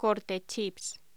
Locución: Corte chips
voz